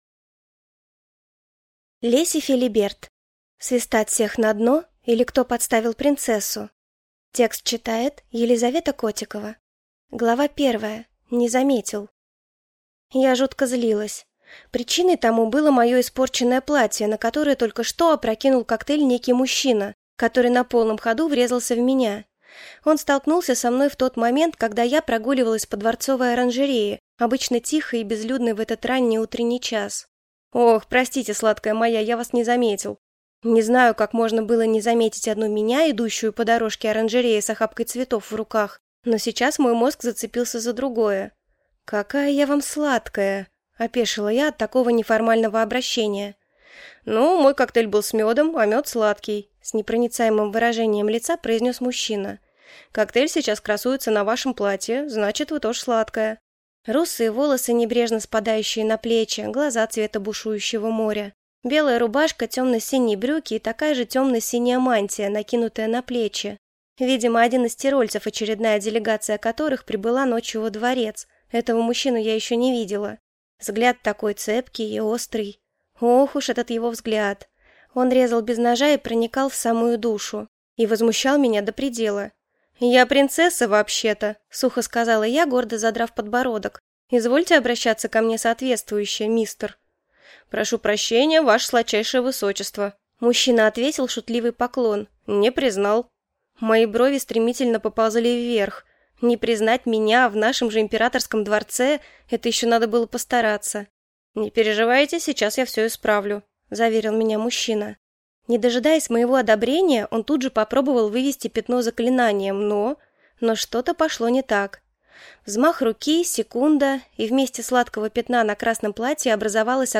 Аудиокнига Свистать всех на дно! или Кто подставил принцессу?